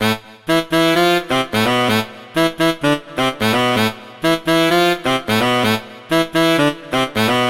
萨克斯风2
描述：巴尔干风格...如果你想使用这个，请告诉我。
Tag: 128 bpm House Loops Brass Loops 1.26 MB wav Key : Unknown